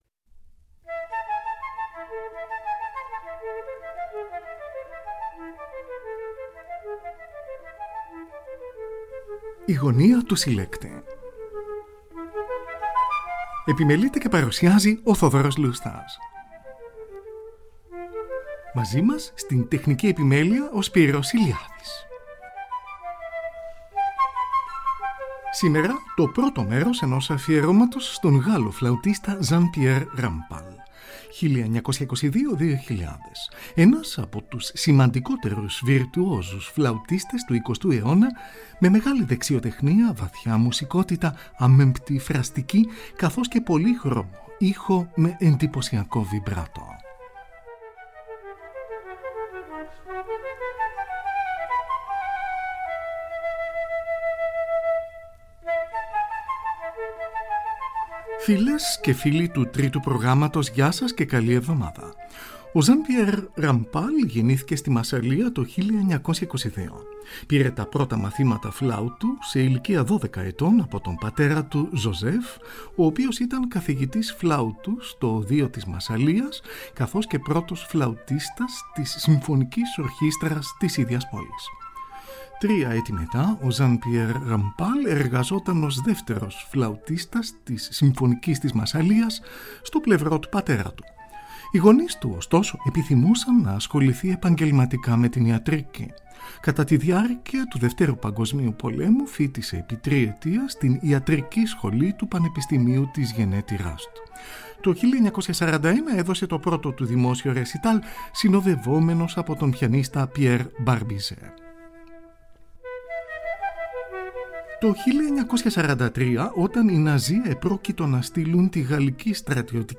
Ακούγονται έργα των Antonio Vivaldi, Johann Sebastian Bach και František Benda.
Εργα για Φλαουτο